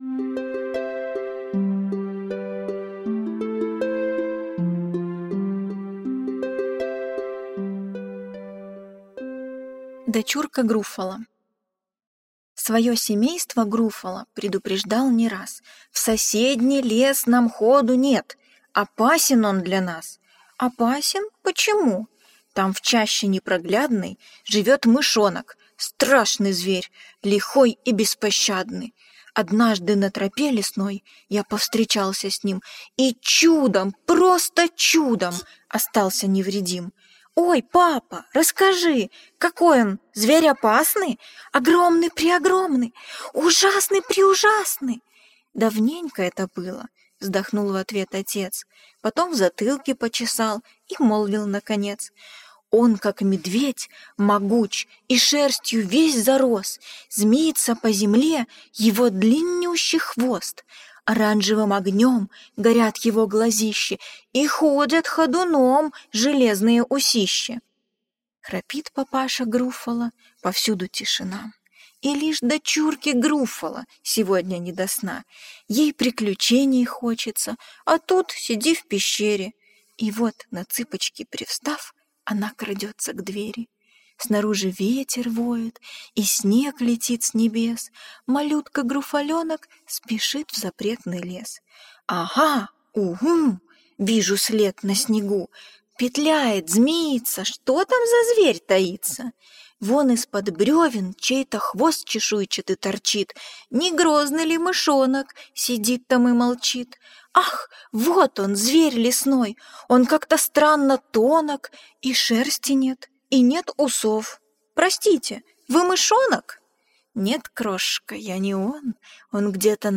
Дочурка Груффало - аудиосказка Джулии Дональдсон - слушать онлайн